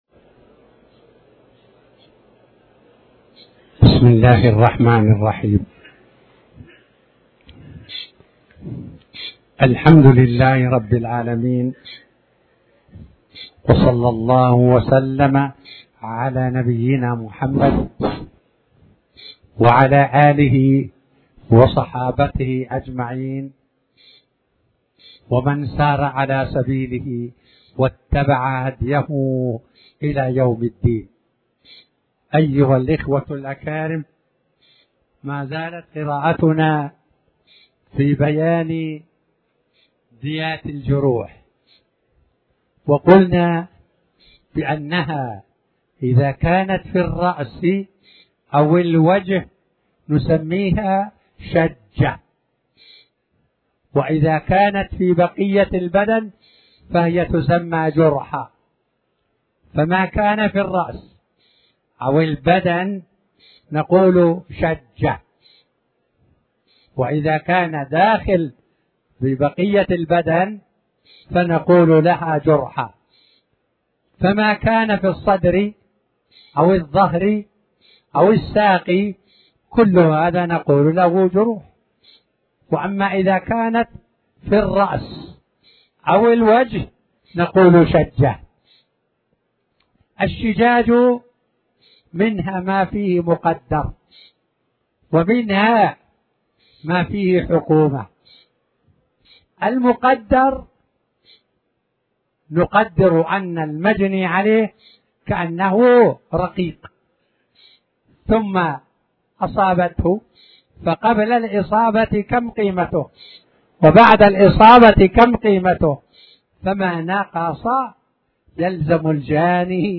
تاريخ النشر ١٦ ذو القعدة ١٤٣٩ هـ المكان: المسجد الحرام الشيخ